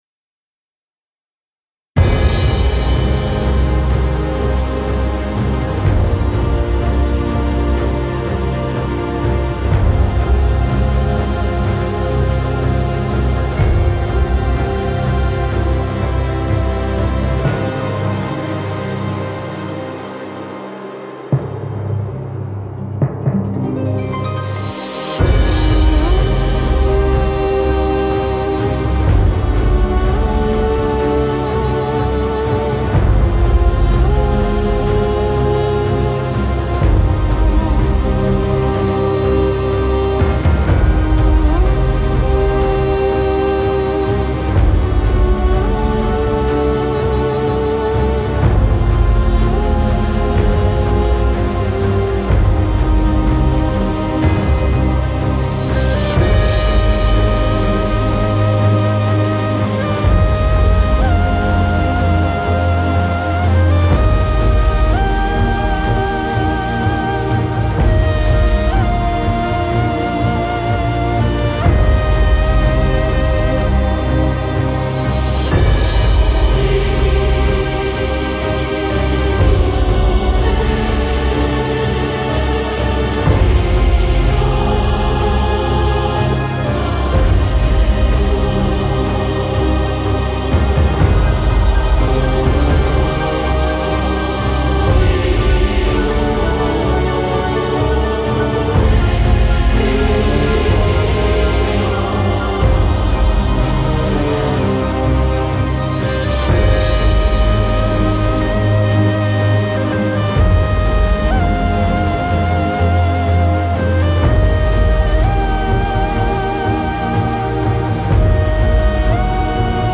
This is a remix
Classical